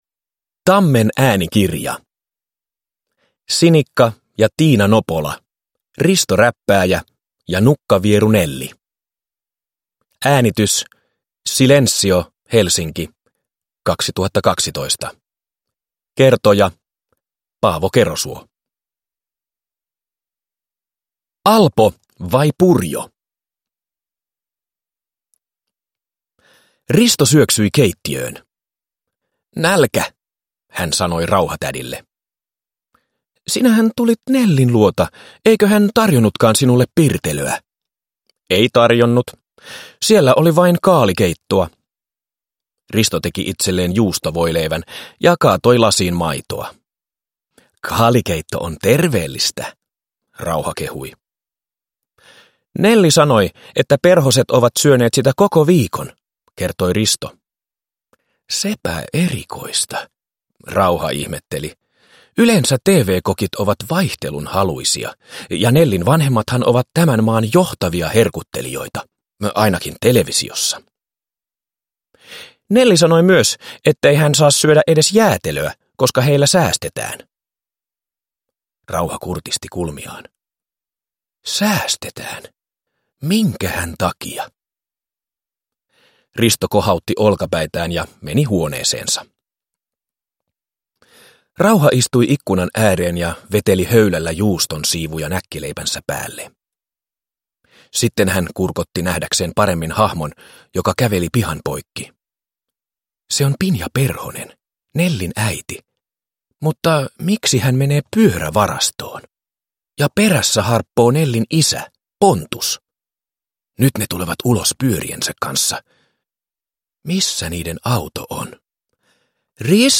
Risto Räppääjä ja nukkavieru Nelli – Ljudbok